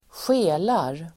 Ladda ner uttalet
skela verb, squintGrammatikkommentar: A &Uttal: [²sj'e:lar] Böjningar: skelade, skelat, skela, skelarDefinition: se i kors, vinda